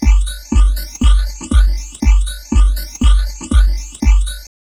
Index of /90_sSampleCDs/USB Soundscan vol.07 - Drum Loops Crazy Processed [AKAI] 1CD/Partition B/07-120FLUID